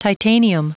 Prononciation du mot titanium en anglais (fichier audio)
Prononciation du mot : titanium